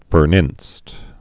(fər-nĭnst)
Chiefly Midland US